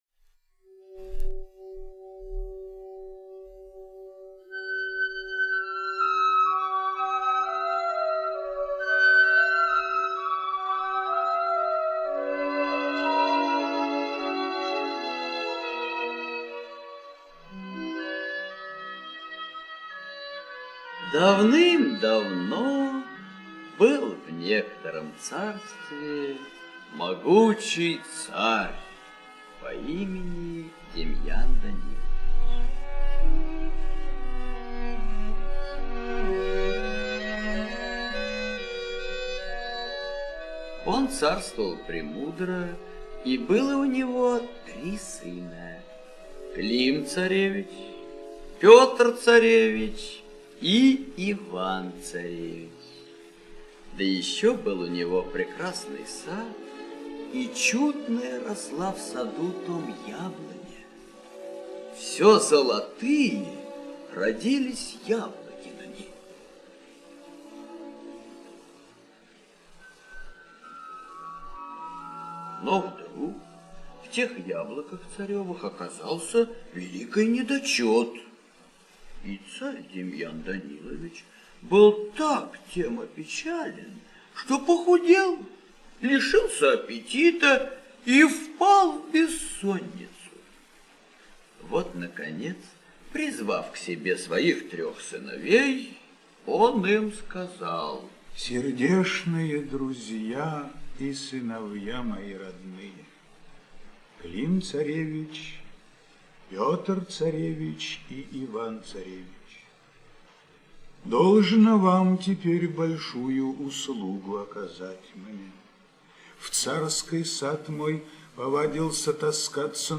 Об Иване-царевиче и Сером Волке - аудиосказка Жуковского